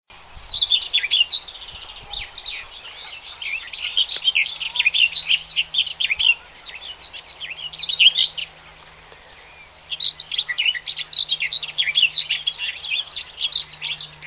Common Whitethroat
scratchy song is once again a typical sound of summer.
CommonWhitethroat3.ogg